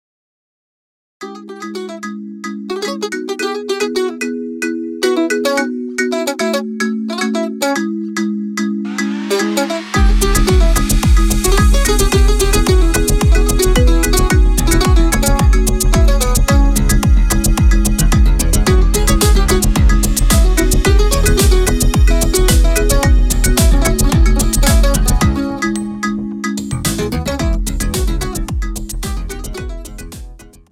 • Type : Instrumental
• Bpm : Allegretto
• Genre : Disco/ funk